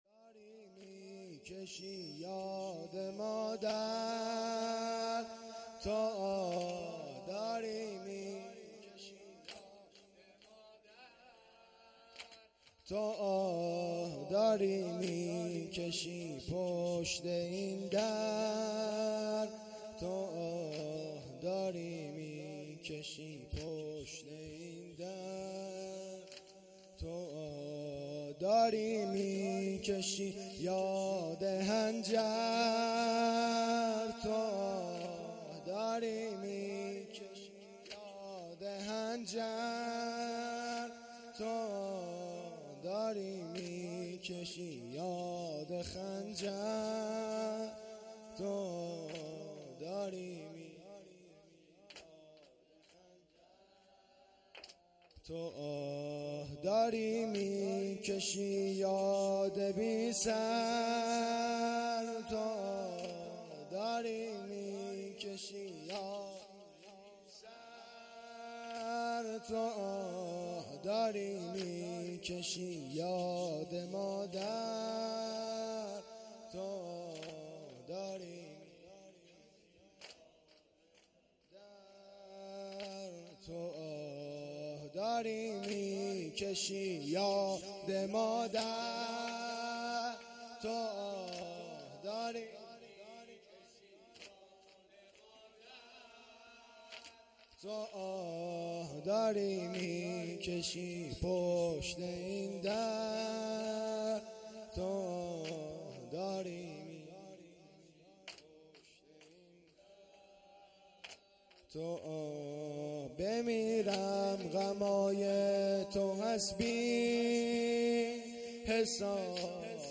ایام شهادت امام جواد علیه السلام